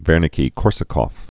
(vĕrnĭ-kē-kôrsə-kôf, -kŏf, -nĭ-kə-)